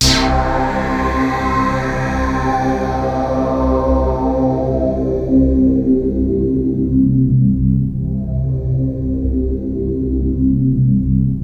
MASTERBASS.wav